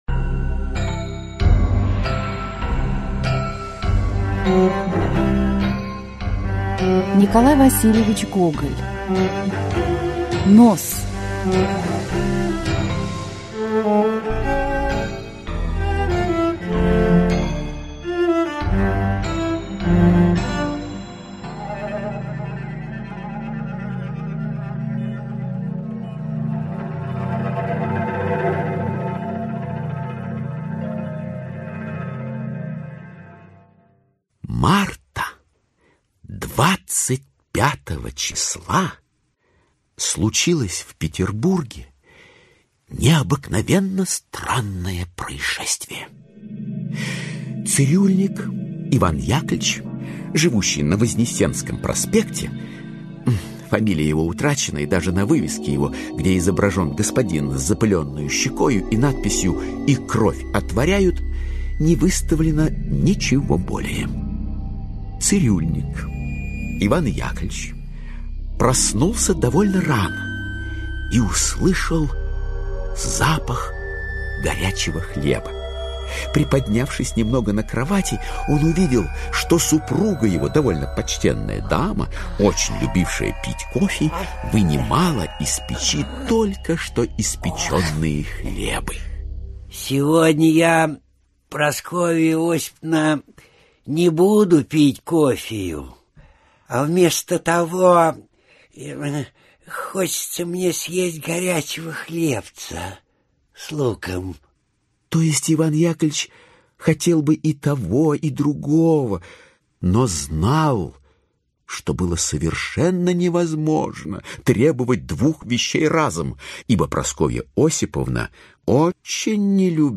Аудиокнига Нос. Аудиоспектакль | Библиотека аудиокниг